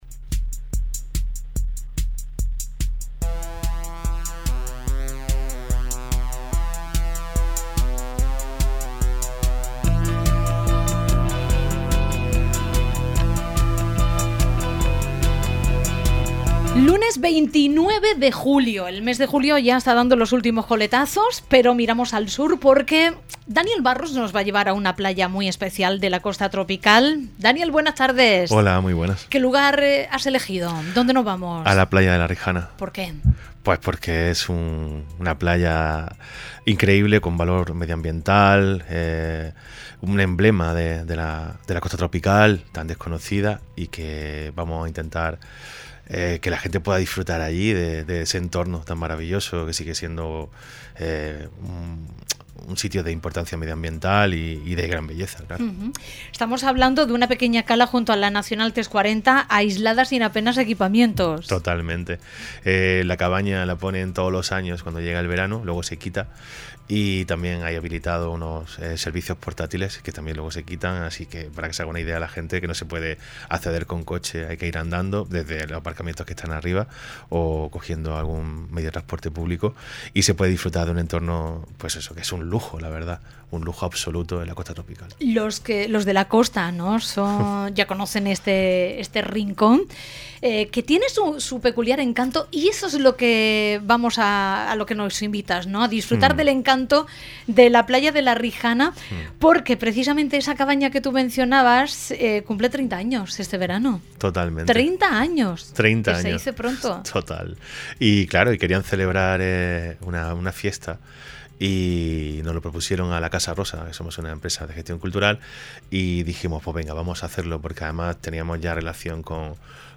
la-casa-rosa-gestion-cultural-la-rijana-aniversario-entrevista-cadena-ser.mp3